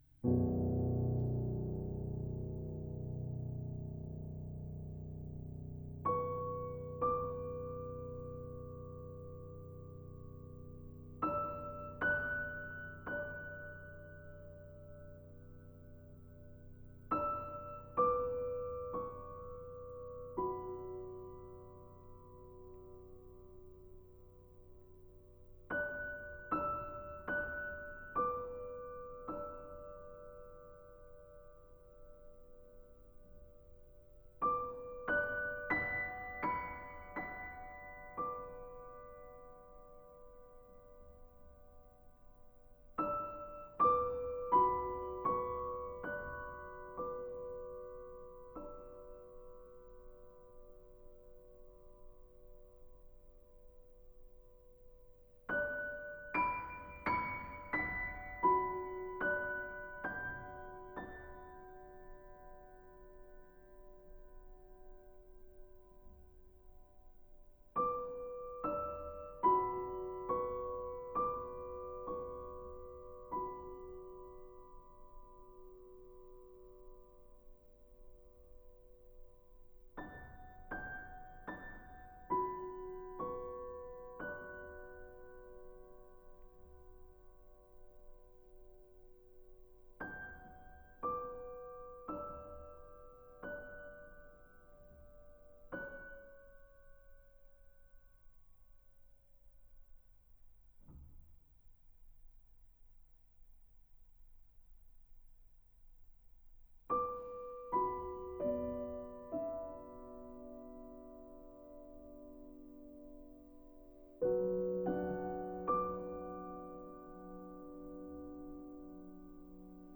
With a 6DB volume boost:
To my ears, it's difficult to tell the original and +6dB versions apart: they both sound fine, though obviously one is slightly louder than the other.